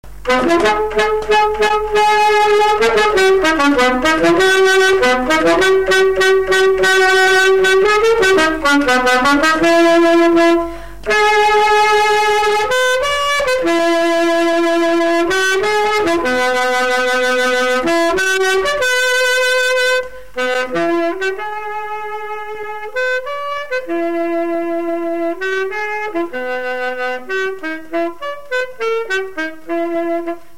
Résumé instrumental
Pièce musicale inédite